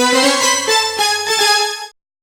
Synth Lick 49-11.wav